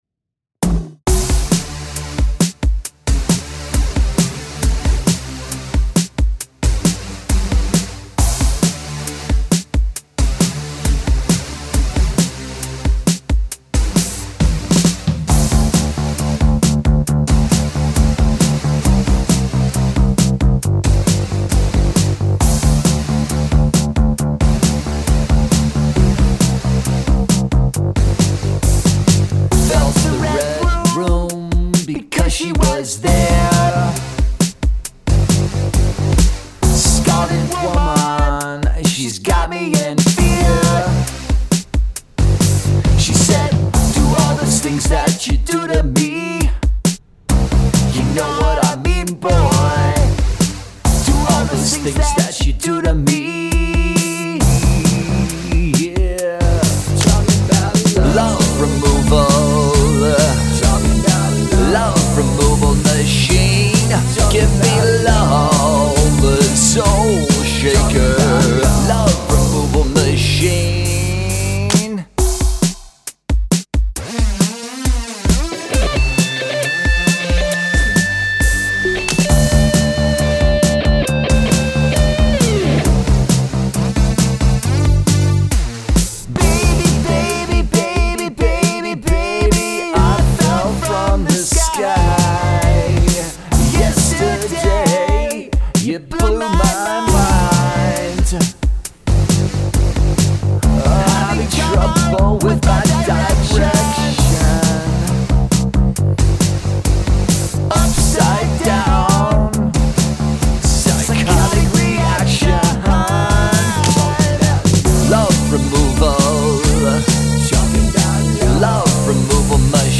Cover song
It is actually a one-man project, c'est moi.